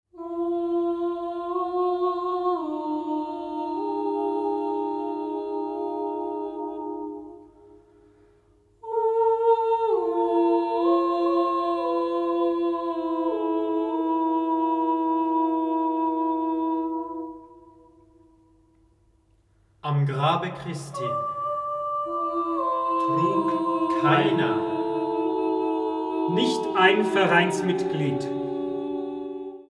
for six-part chamber choir
Description:Classical; choral music;
Instrumentation:Six-part chamber choir
(S Mz A T Ba B)